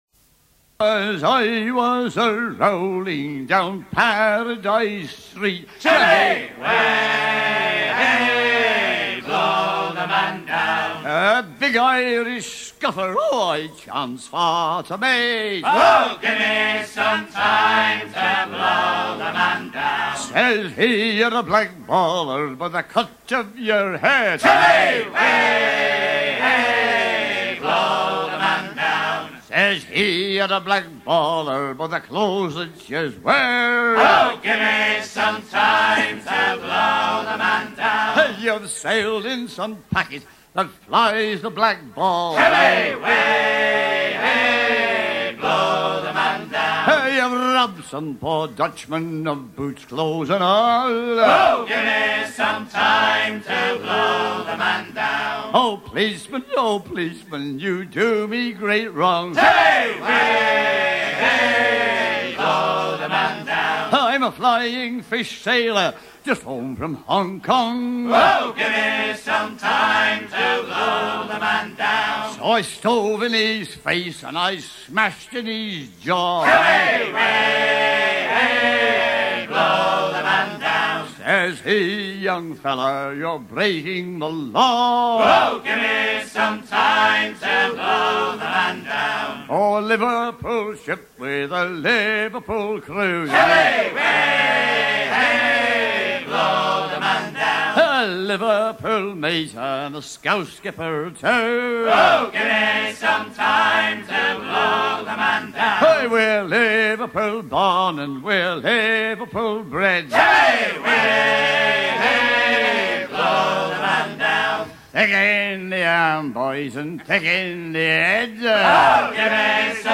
shantie anglais apprécié des marins de Liverpool
Pièce musicale éditée